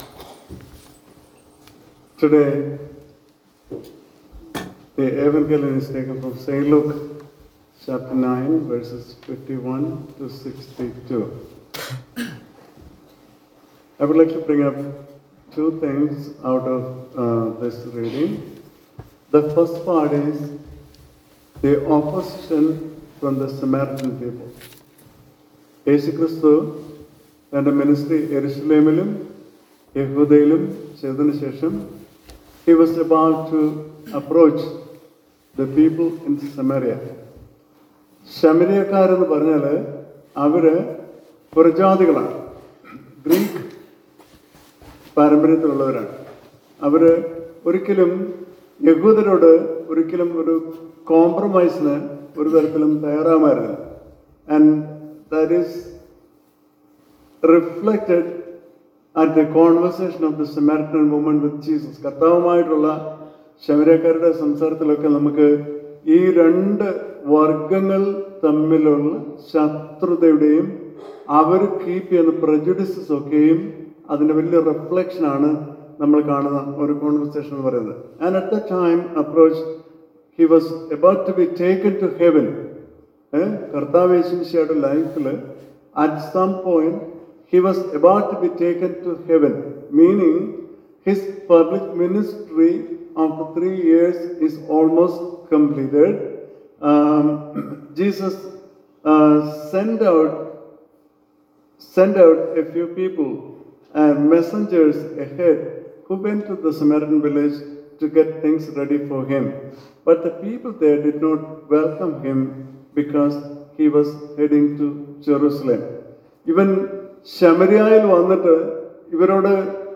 Sermon – May 25-2025 – St. Luke 9: 51-62 - St Mary's Indian Orthodox Church of Rockland